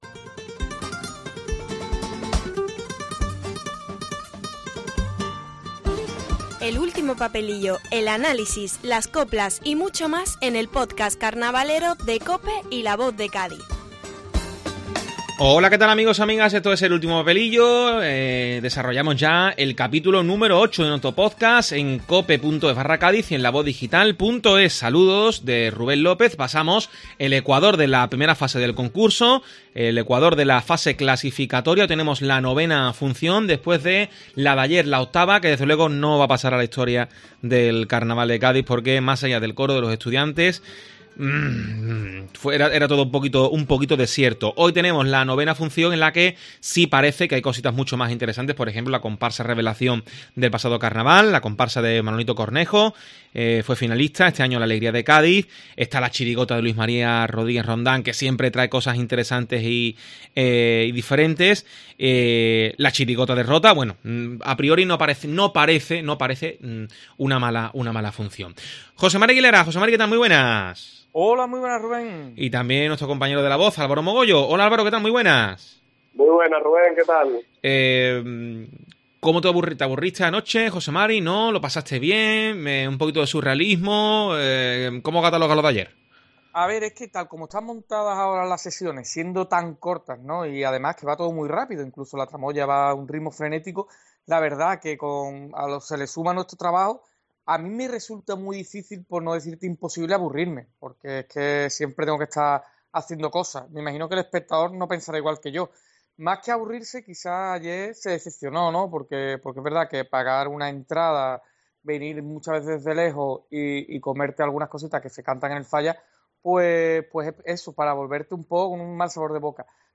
Escucha el tiempo de debate y análisis tras la octava función de clasificatorias del COAC 2024